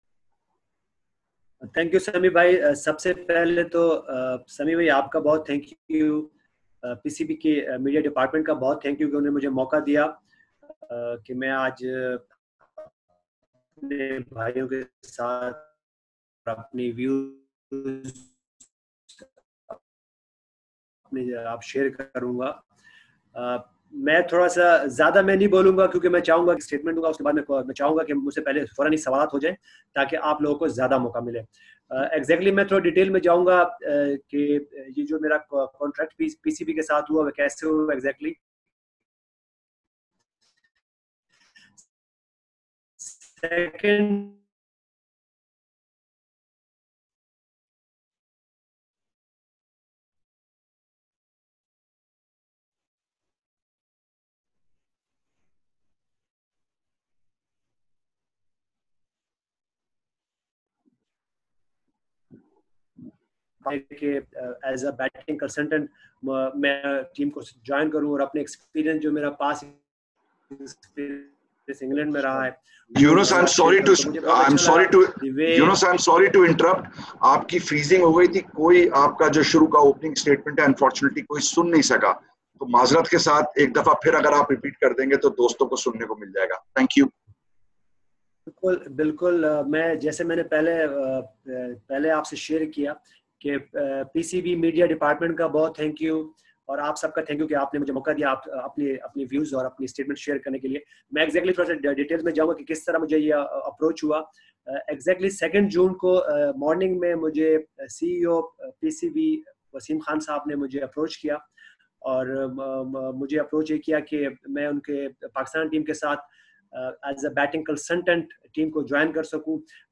Mushtaq Ahmed and Younis Khan, the spin bowling and batting coaches of the Pakistan national men’s team for the tour of England, held interactions with the local media via video conference calls today.